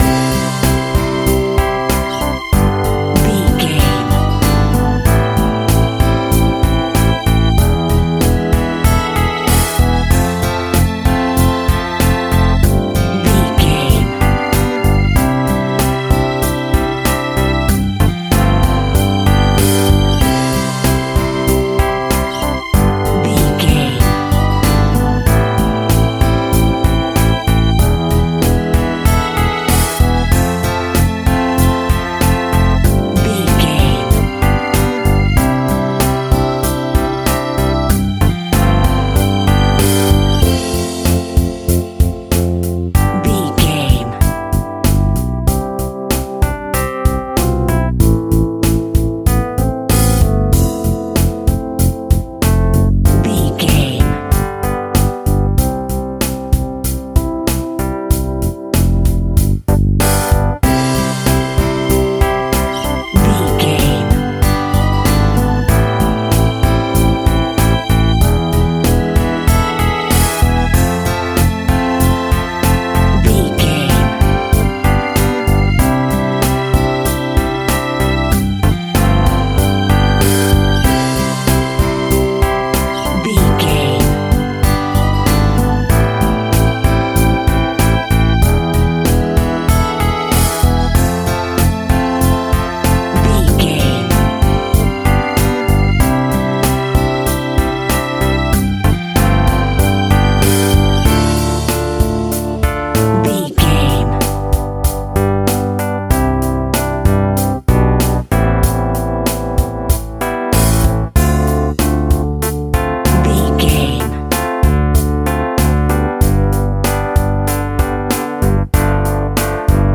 Anthem Pop Rock.
Ionian/Major
happy
uplifting
drums
bass guitar
acoustic guitar
synth keys
strings